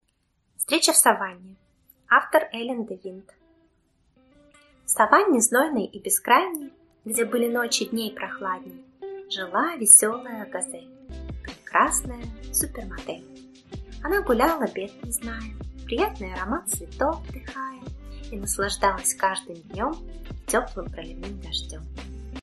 Аудиокнига Встреча в саванне | Библиотека аудиокниг